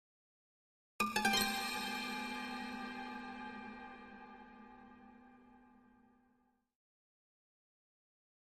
String Pluck Chord Strike With Reverb 1